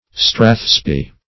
Search Result for " strathspey" : The Collaborative International Dictionary of English v.0.48: Strathspey \Strath"spey`\, n. [So called from the district of Strath Spey in Scotland.] A lively Scottish dance, resembling the reel, but slower; also, the tune.